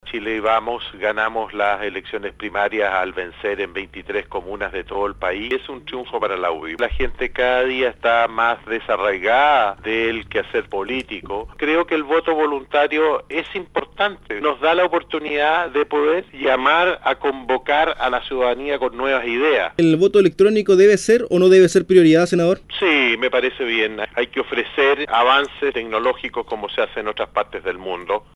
En otras materias, y en conversación con Radio Sago, el Senador UDI marcó distancia en torno al veto presidencial aplicado sobre aspectos de la Reforma Laboral, elevando fuertes críticas hacia el proyecto.